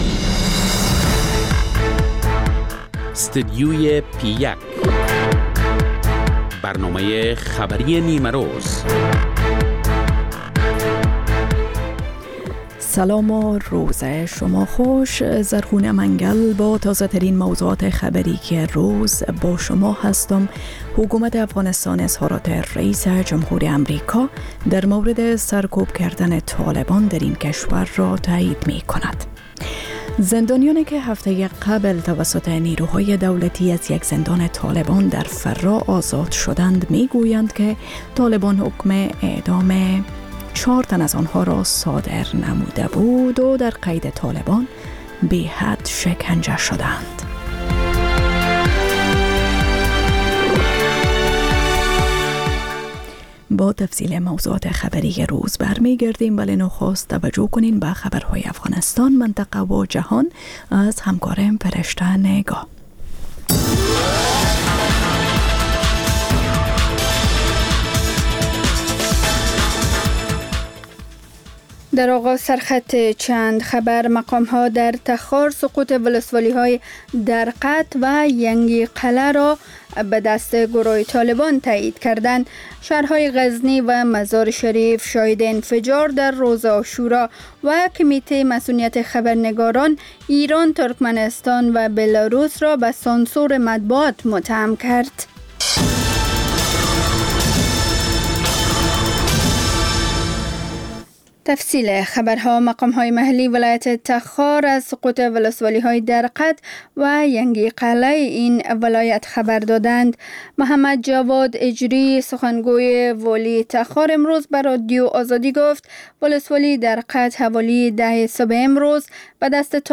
مجله خبری نیمه روز - ستودیوی P1
پخش زنده - رادیو آزادی